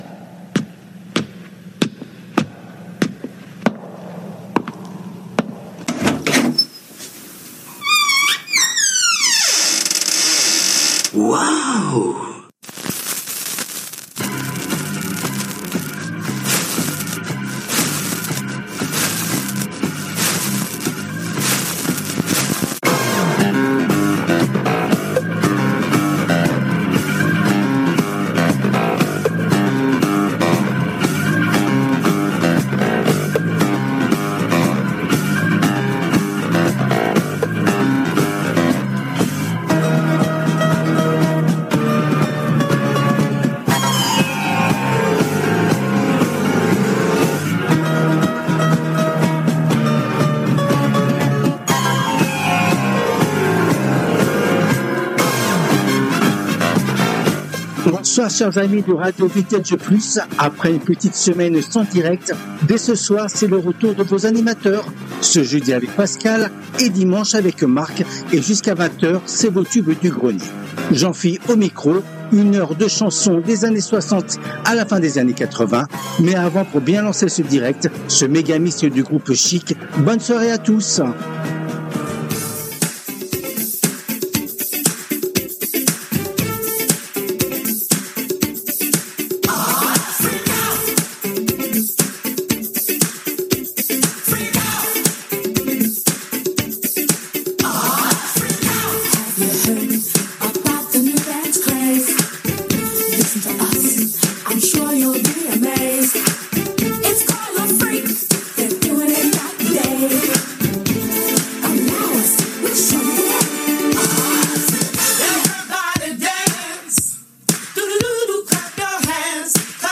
Après une semaine de pause, RADIO VINTAGE PLUS reprend ses directs avec les TUBES DU GRENIER, première émission hebdomadaire phare de la semaine sur RADIO VINTAGE PLUS. Elle a été diffusée en direct le mardi 18 février 2025 à 19h depuis les studios de RADIO RV+ à PARIS .
Les Tubes connus ou oubliés des 60's, 70's et 80's